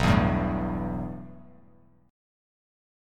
B7sus4#5 chord